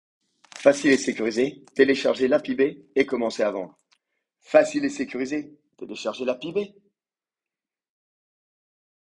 Casting voix-off pub eBay